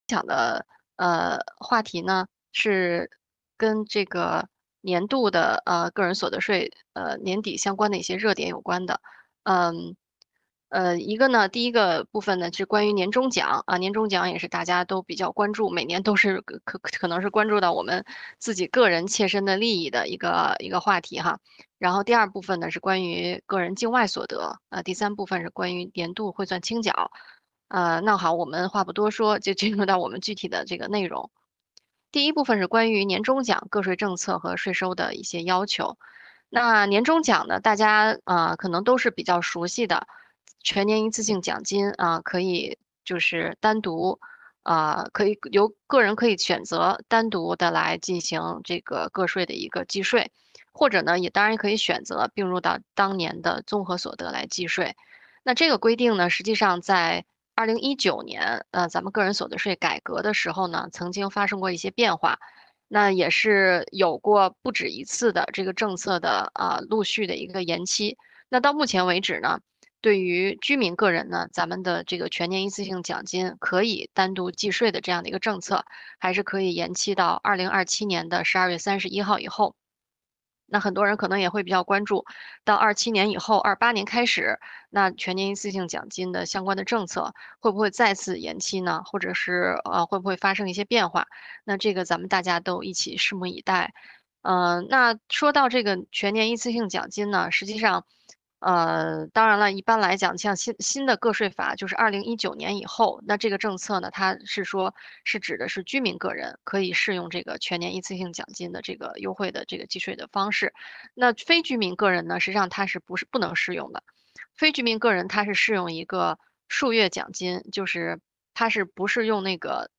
视频会议